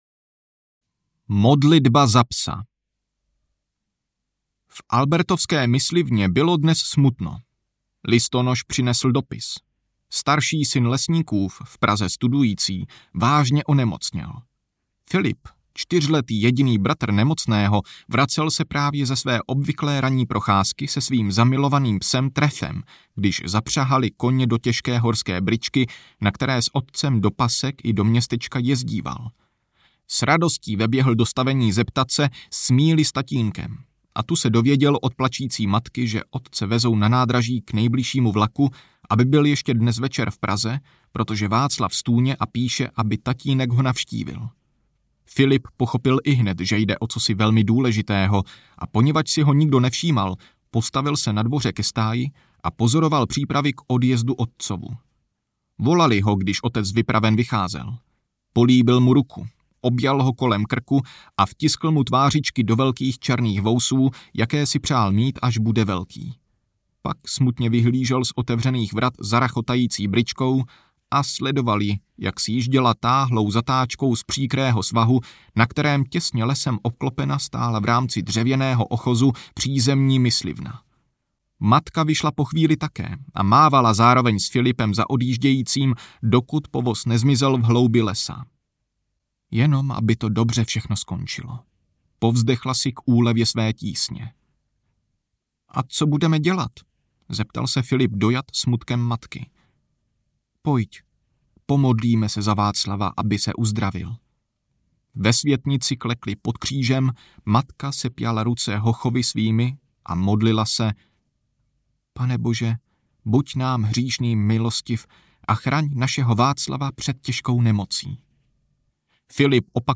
Povídky o dětech audiokniha
Ukázka z knihy